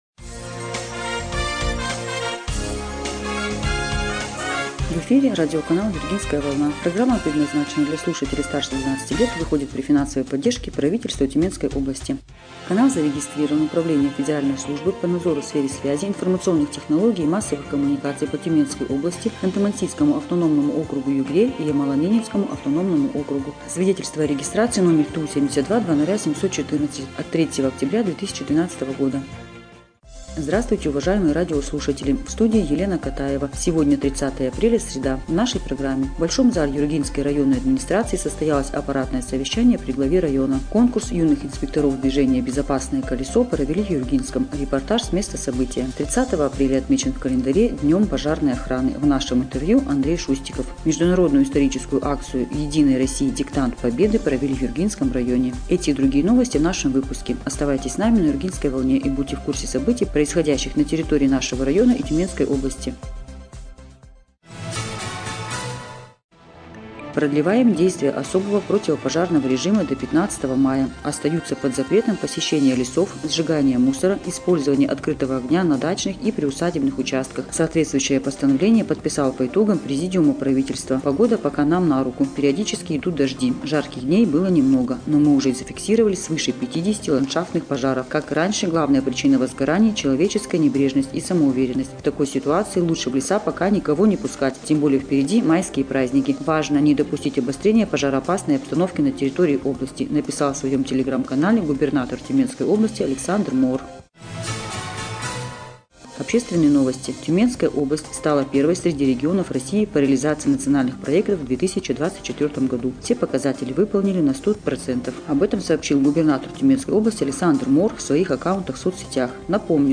Эфир радиопрограммы "Юргинская волна" от 30 апреля 2025 года
Новости Юргинского района